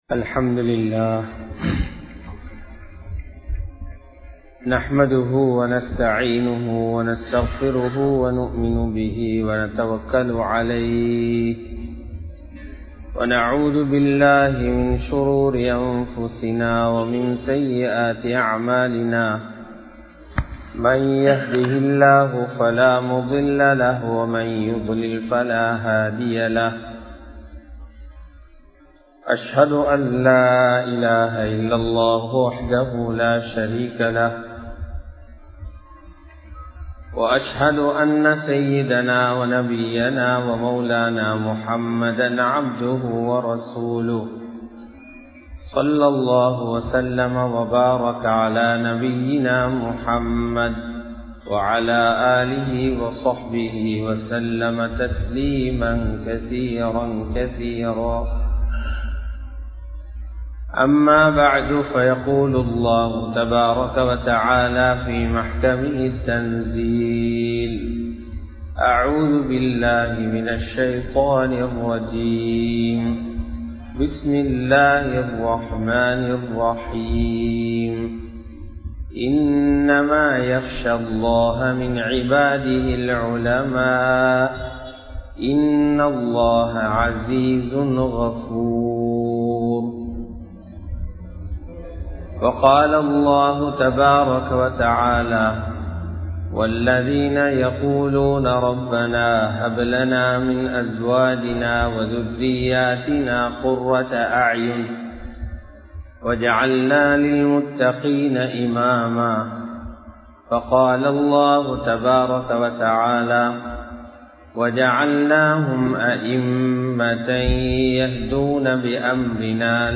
Ulamakkal Entraal Yaar? (உலமாக்கள் என்றால் யார்?) | Audio Bayans | All Ceylon Muslim Youth Community | Addalaichenai
Warakamura Jumua Masjidh